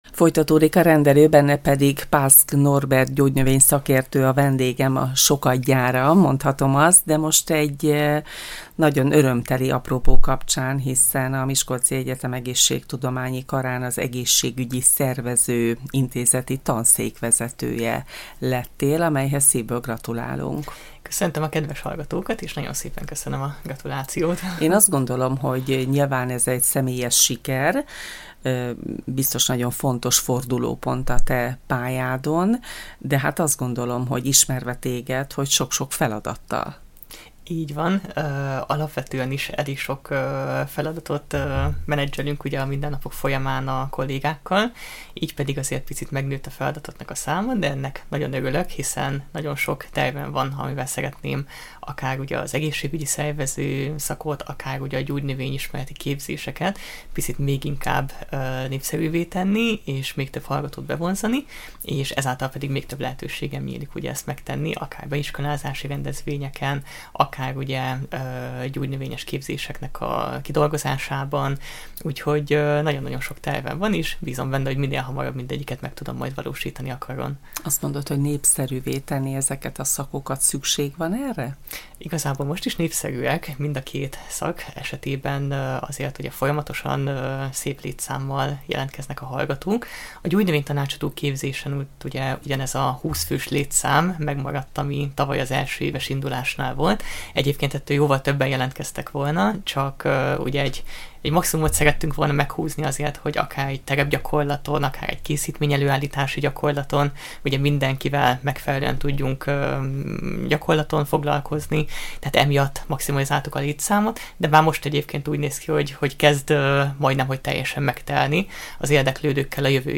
Bővülő feladatokról, új kihívásokról is beszélgettünk vele. Természetesen szóba kerültek a tavasz közismert gyógynövényei, az egyetemi gyógynövényes kert kialakítása, s a szerveződő második Miskolci Gyógynövény Konferencia, amelyet idén november 14-én és 15-én rendeznek.